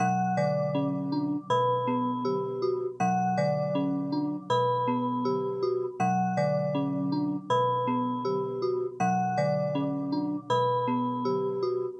描述：电子低频黑暗环境的洗涤
标签： 低音 暗环境 电子 洗净
声道立体声